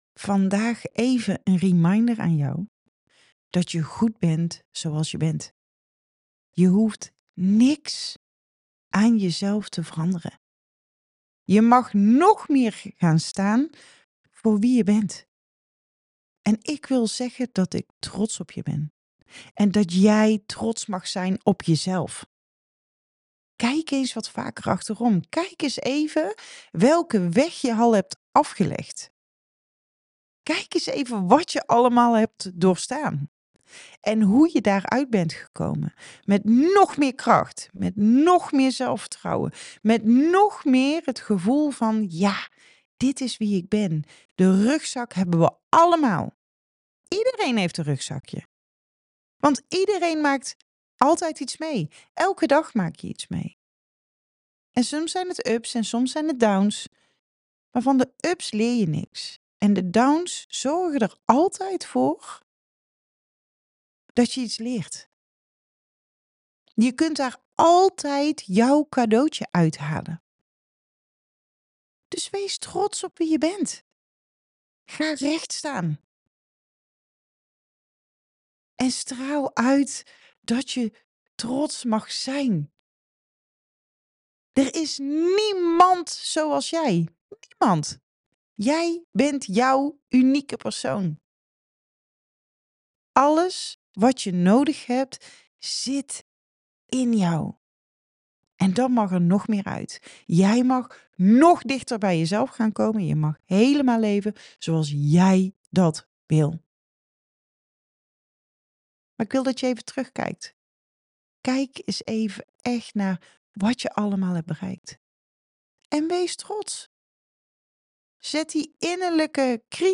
In het voicebericht van vandaag ontdek je hoe belangrijk het is om trots te zijn op jezelf.